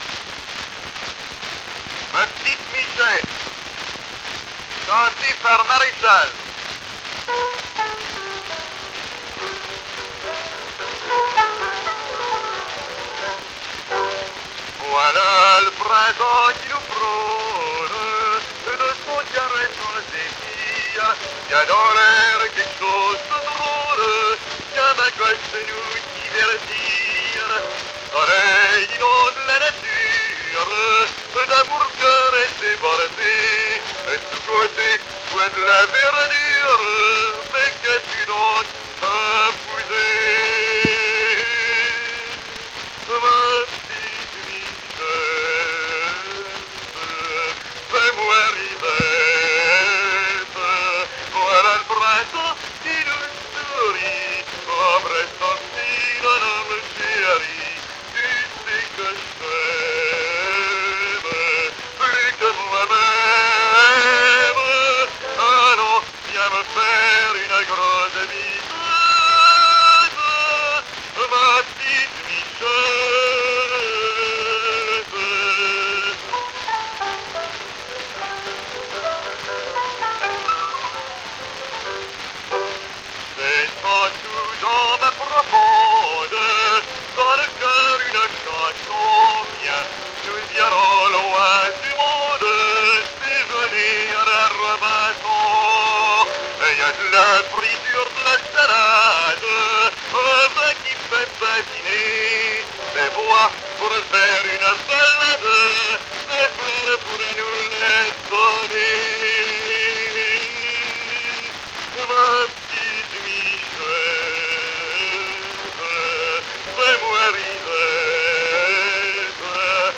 mit Klavierbegleitung